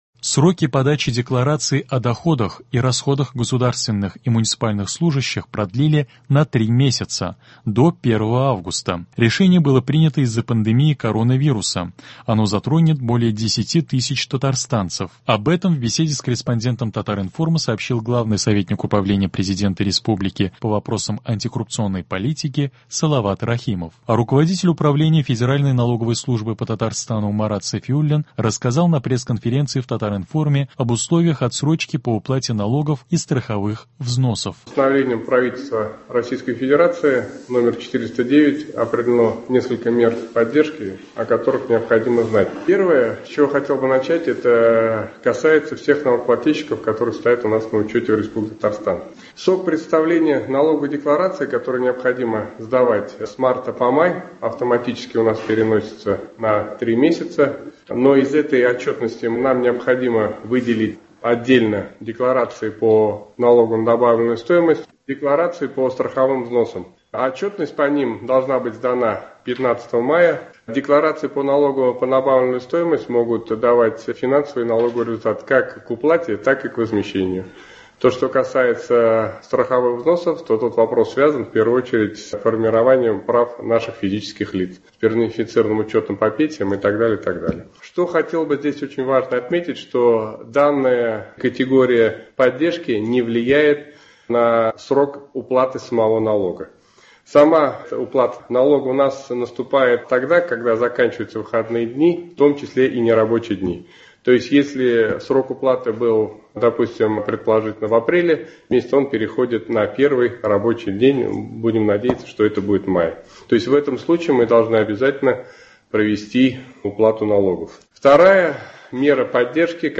Руководитель Управления Федеральной налоговой службы по Татарстану Марат Сафиуллин рассказал на пресс- конференции в Татар-информе об условиях отсрочки по уплате налогов и страховых взносов.